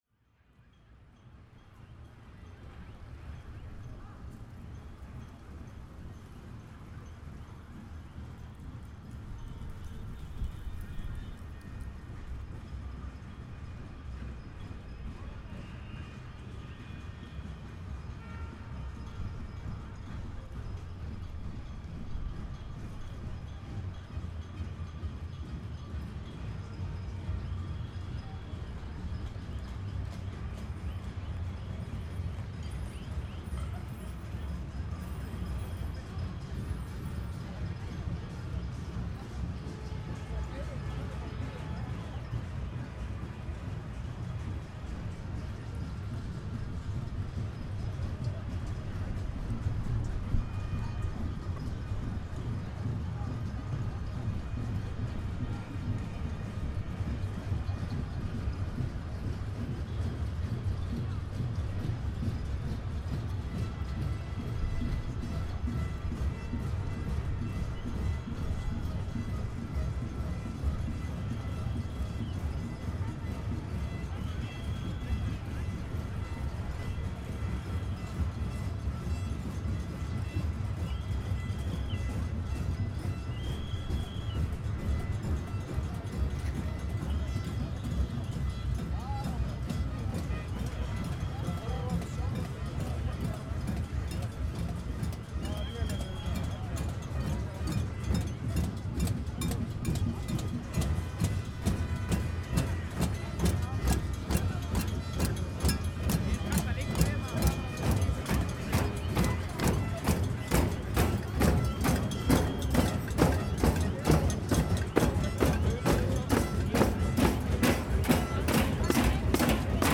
Hér er á ferðinni upptaka sem tekin var upp á einum fjölmennustu mótmælum Íslandssögunar, en talið er að u.þ.b. 8 þúsund manns hafi mætt á svæðið.